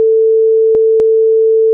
Stereo.
You will hear two clics, one at t = 0.75, when the second channel starts to play, and the other at t = 1, when the first channel stops playing; this effect can be avoided by applying an appropiate envelope (see other examples below).